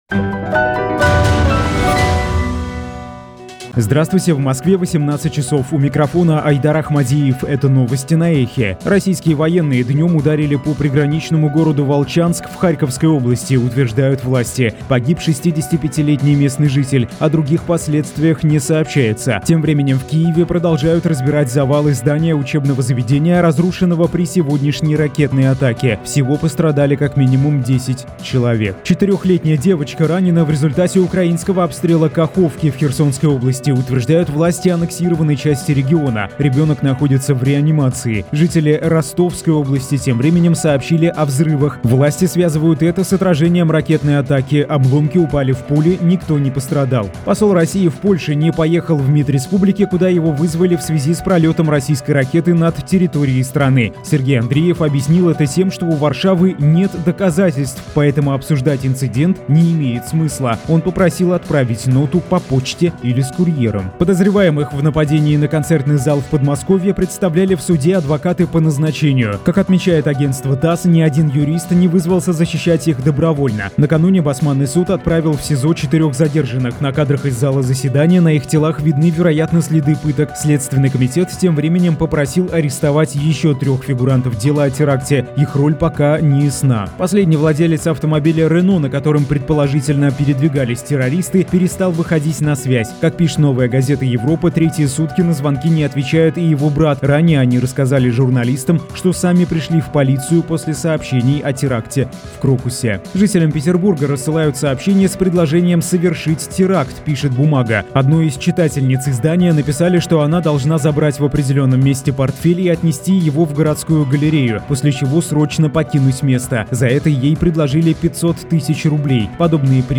Слушайте свежий выпуск новостей «Эха».
Новости